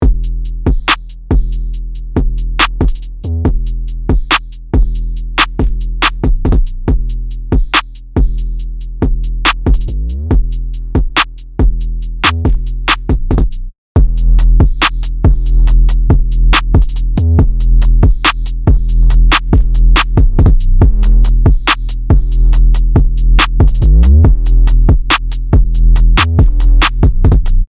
Drum Machine | Preset: Crunch Kit Edge (DRY→WET)
Articulate-Drum-Machine-Crunch-Kit-Edge-2.mp3